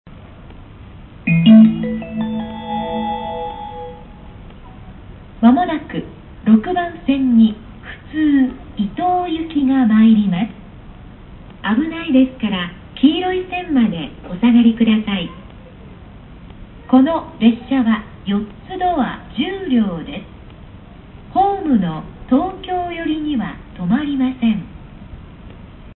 接近放送普通伊東行き4ドア10両普通伊東行き4ドア10両の接近放送です。
「東京寄りには止まりません」となり、ツギハギが無くなった。